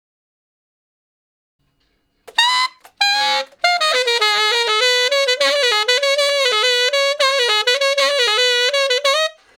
068 Ten Sax Straight (Ab) 22.wav